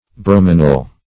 Search Result for " bromanil" : The Collaborative International Dictionary of English v.0.48: Bromanil \Brom`an"il\, n. [Bromine + aniline.]